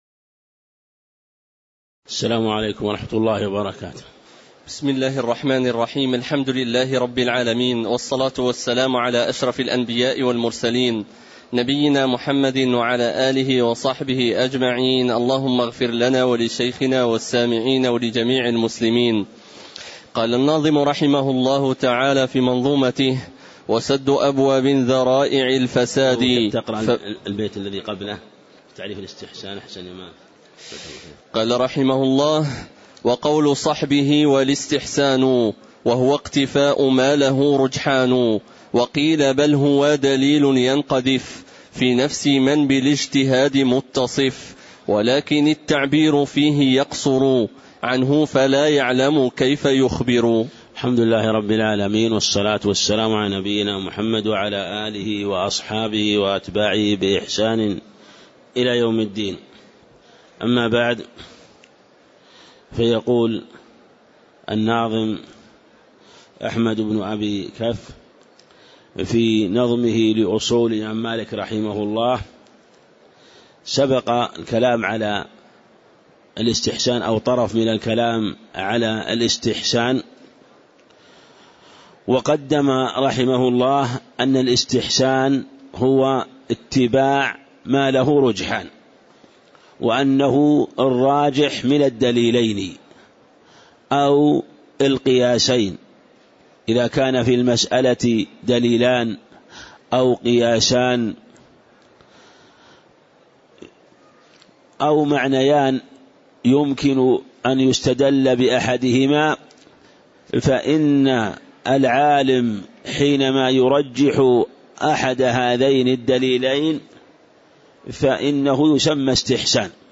تاريخ النشر ٦ جمادى الآخرة ١٤٣٧ هـ المكان: المسجد النبوي الشيخ